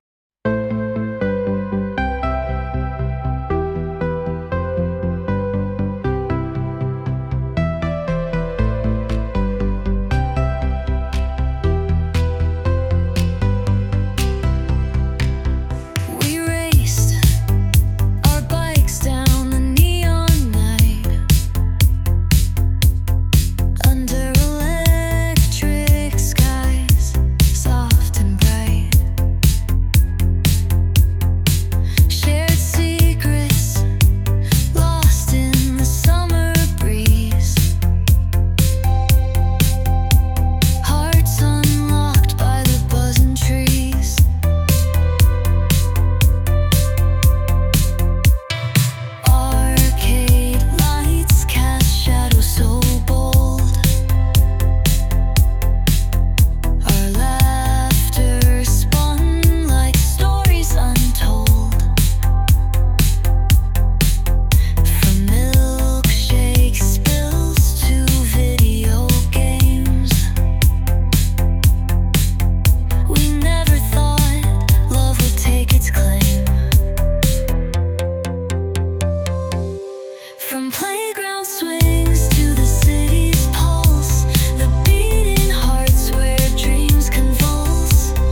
nostalgic mood track